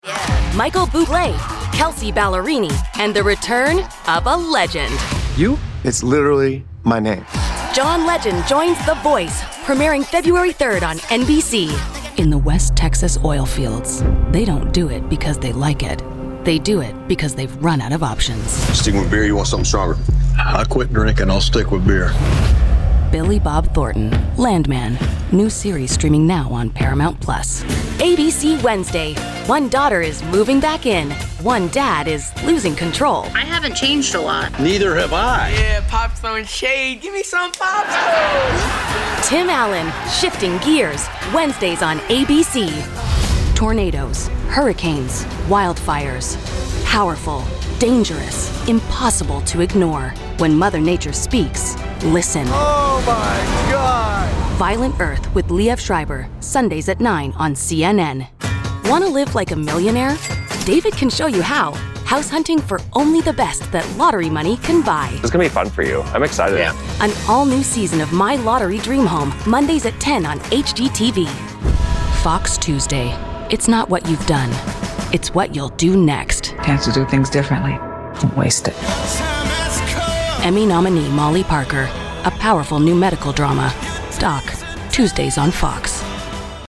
Promo
English - Midwestern U.S. English
An authentic, conversational, cool voice that's not announcer-y. Unless you want that.